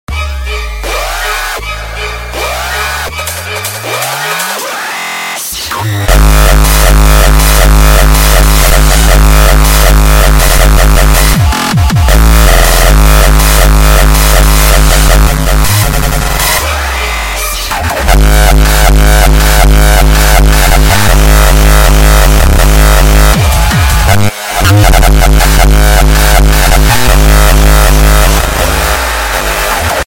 Techno - Rawstyle - Rawtempo - Zaagkicks